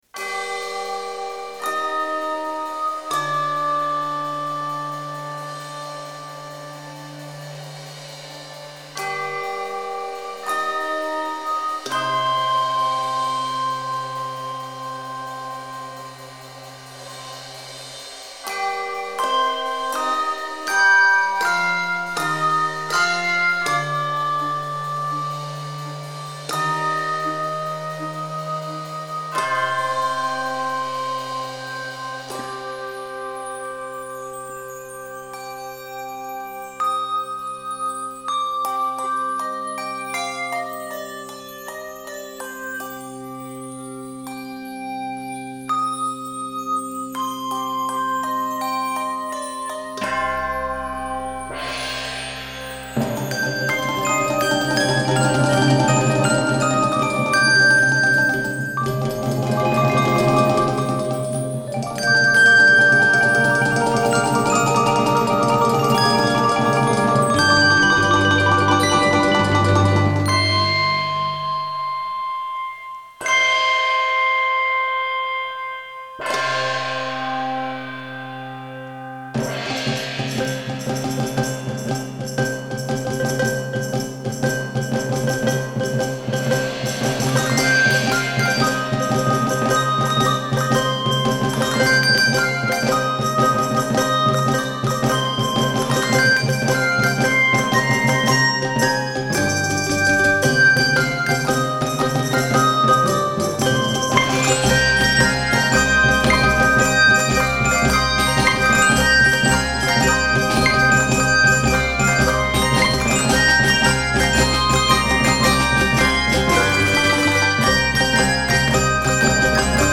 handbells
handchimes
Enjoy! 106 measures in c minor.